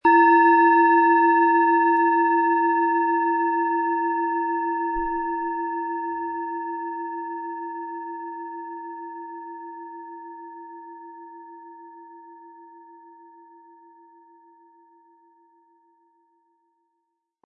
Planetenton 1
Wie klingt diese tibetische Klangschale mit dem Planetenton Chiron?
Im Sound-Player - Jetzt reinhören können Sie den Original-Ton genau dieser Schale anhören.